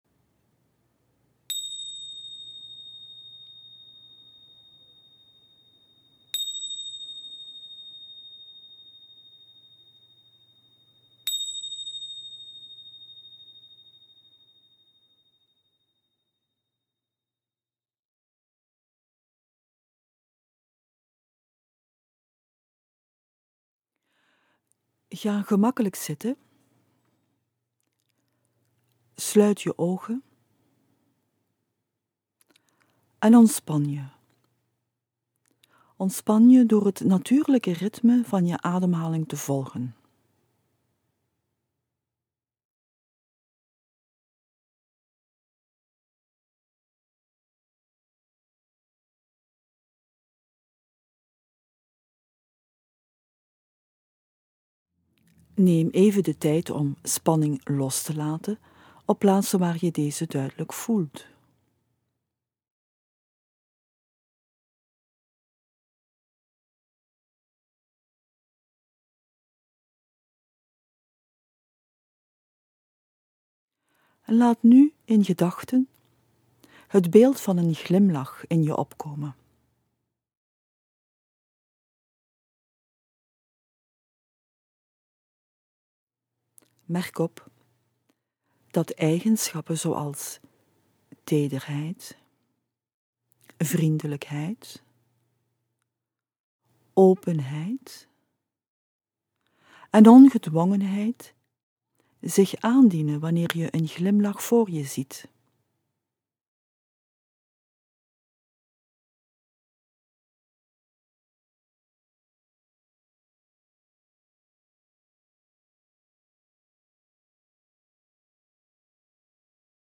Deze meditatie is een oefening met vocale begeleiding (dit kon ik niet onder het forum plaatsen)
glimlach.mp3 (5.5 MB)